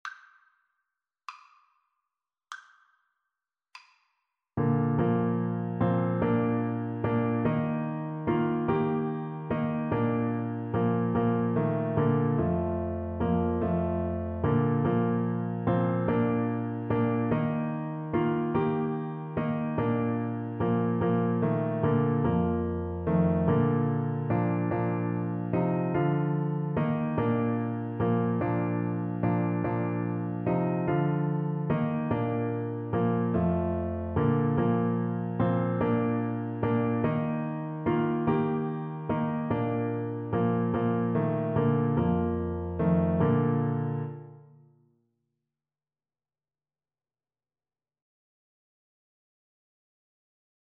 Christian
Alto Saxophone
6/8 (View more 6/8 Music)
Bb4-Bb5
Classical (View more Classical Saxophone Music)